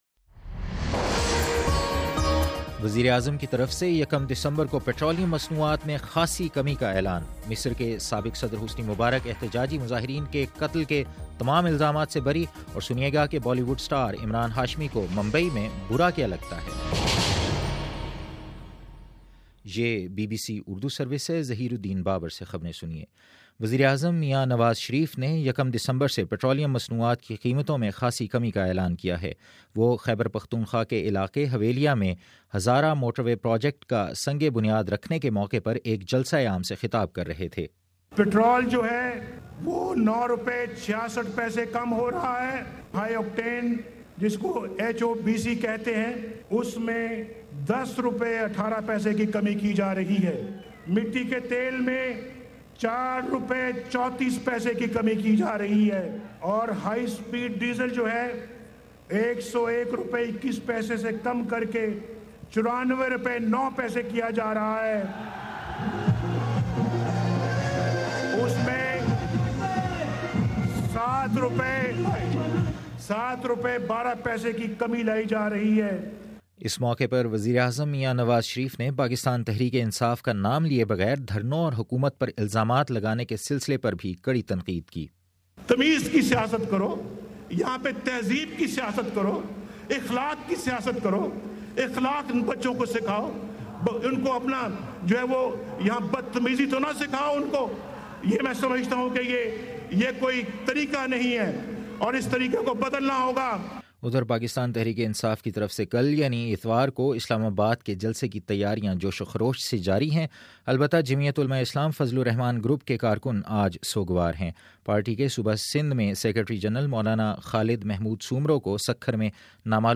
نومبر29 : شام چھ بجے کا نیوز بُلیٹن